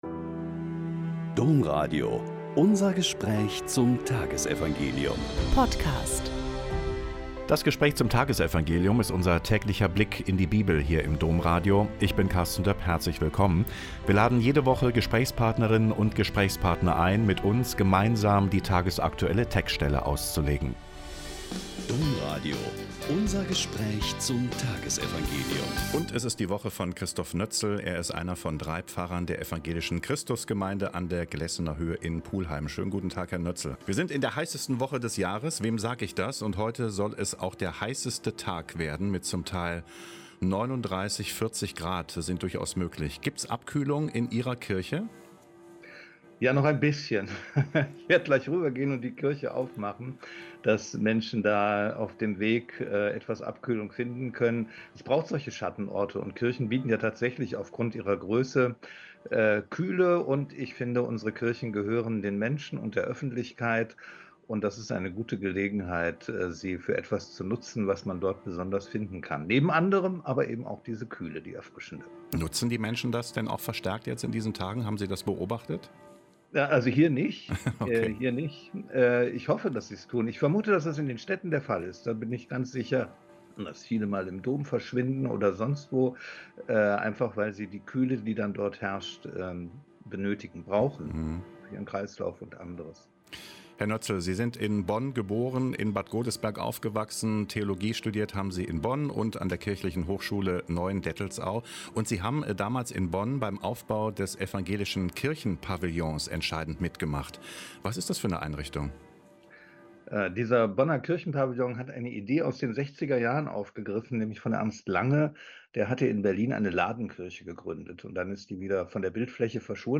Lk 1, 39–56 - Gespräch